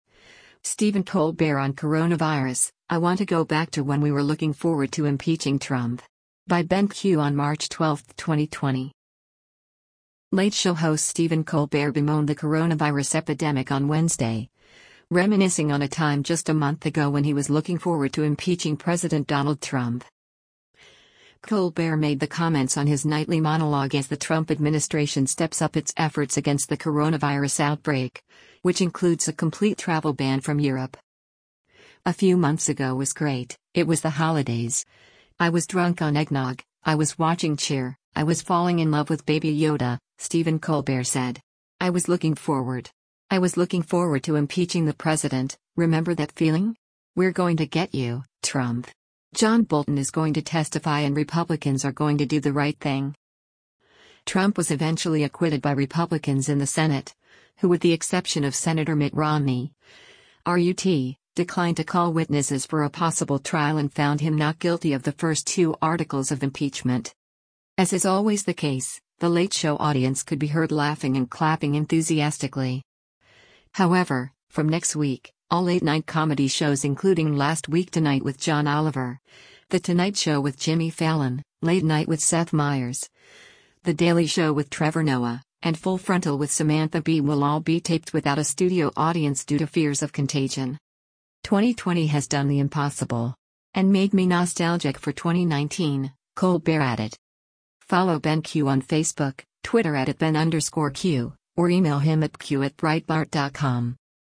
Colbert made the comments on his nightly monologue as the Trump administration steps up its efforts against the coronavirus outbreak, which includes a complete travel ban from Europe.
As is always the case, the Late Show audience could be heard laughing and clapping enthusiastically.